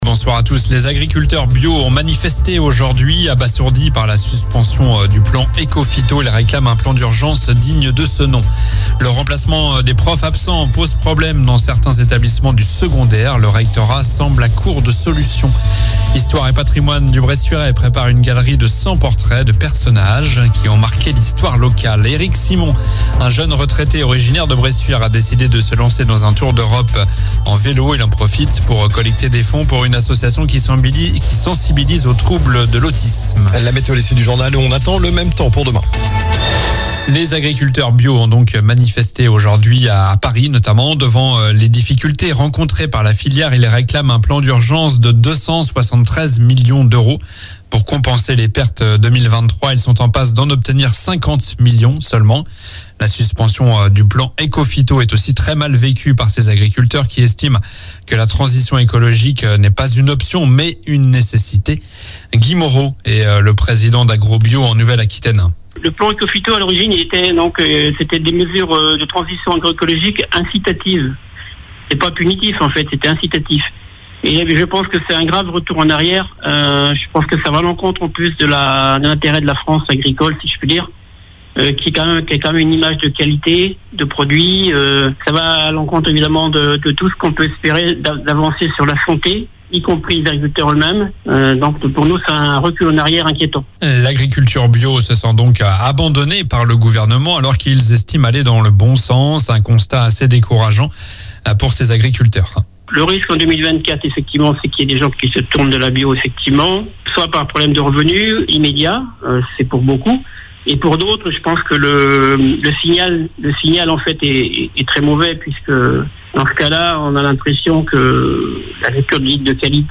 Journal du mercredi 07 février (soir)